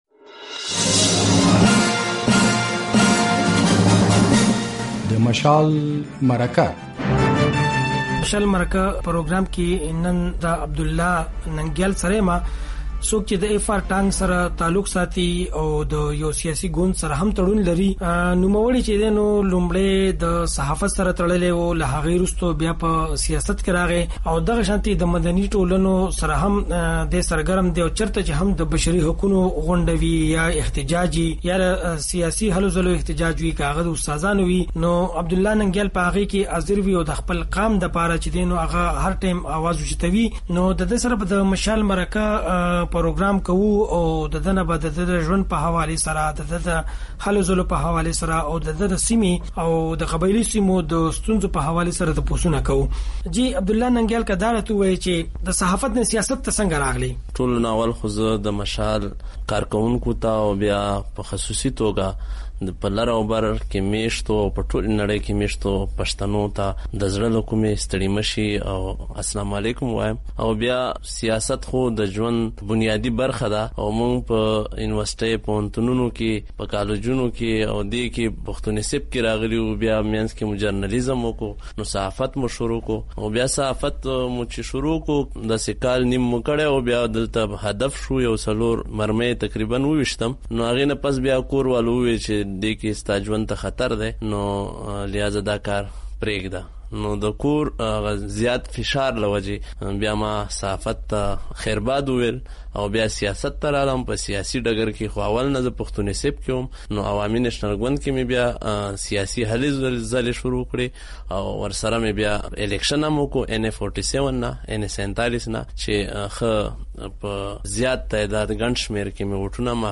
مشال مرکه